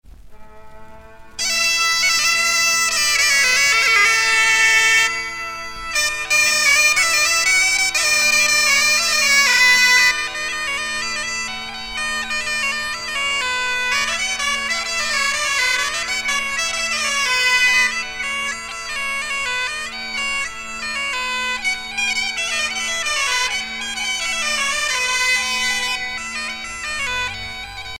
danse : dérobées
Pièce musicale éditée